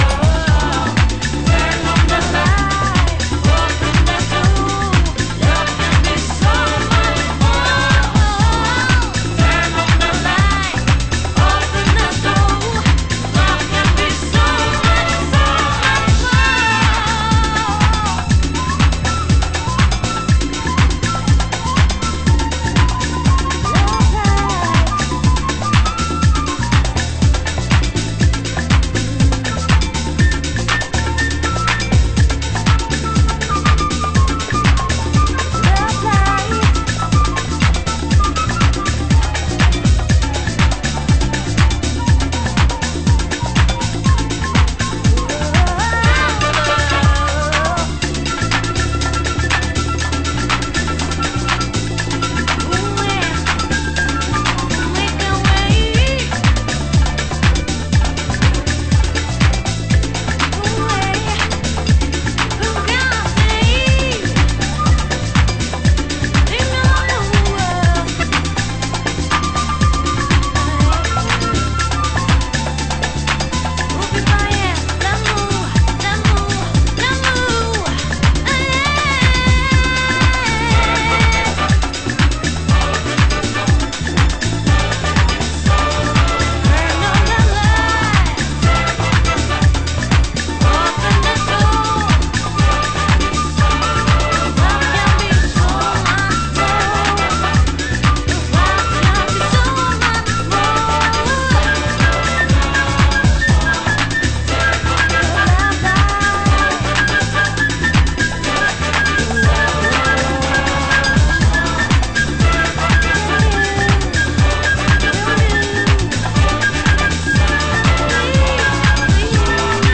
ハウス専門店KENTRECORD（ケントレコード）